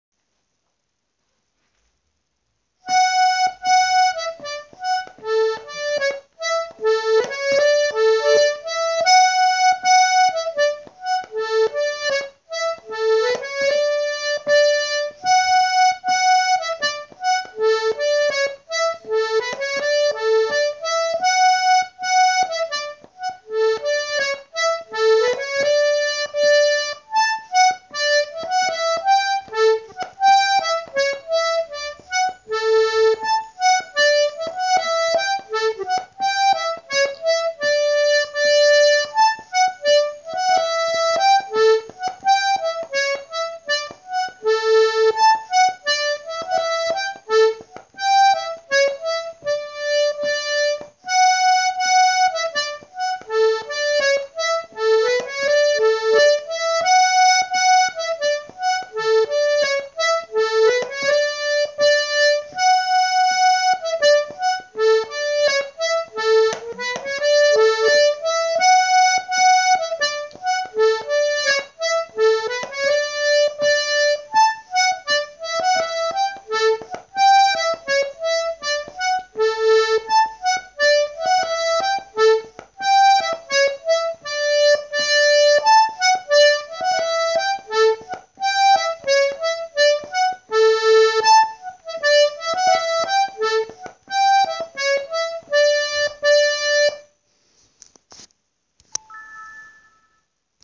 Bhí na píosaí casta go han-mhall ar an dtaifead sin ach tá siad anseo arís- casta ag gnáthluas agus casta níos fearr!!